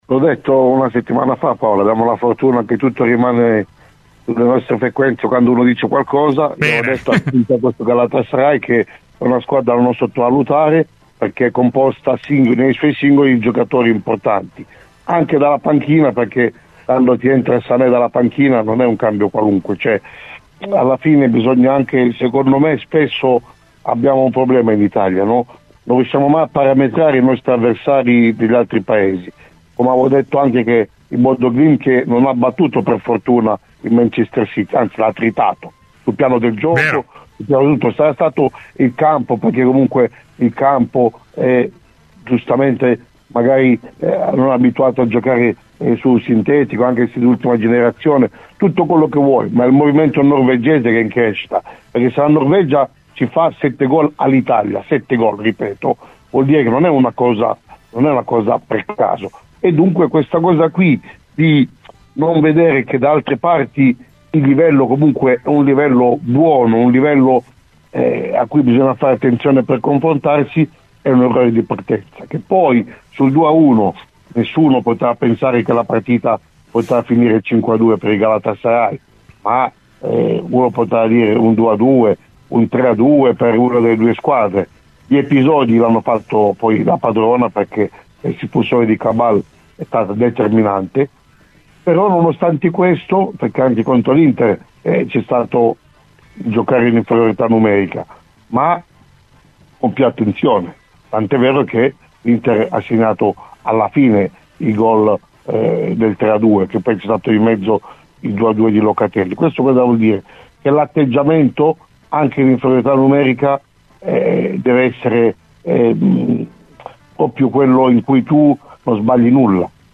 Nel corso del suo intervento a "Cose di Calcio", su Radio Bianconera